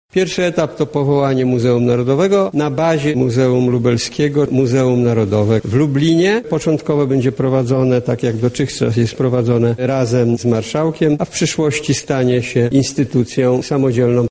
Jak podkreśla wicepremier Piotr Gliński nasz region zasługiwał na podniesienie tak sprawnie działającej placówki do rangi instytucji muzealnej narodowej.